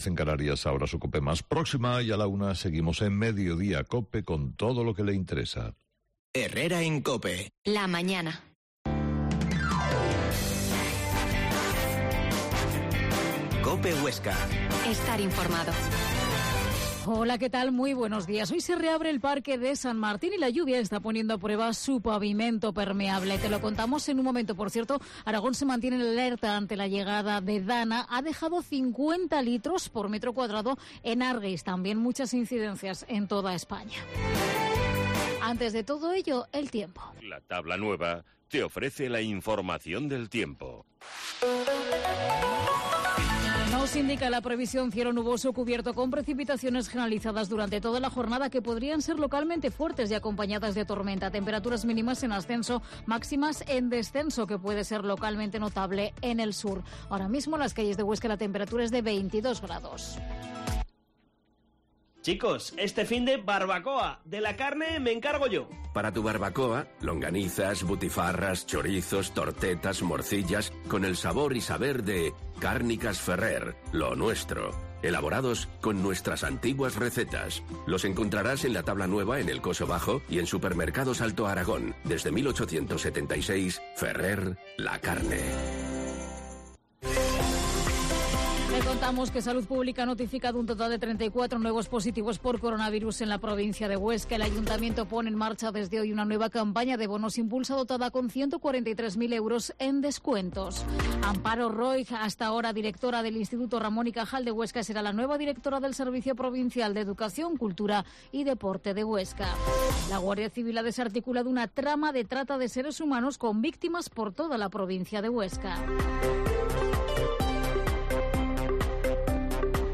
Herrera en Cope Huesca 12,50h. Entrevista al concejal de Medio Ambiente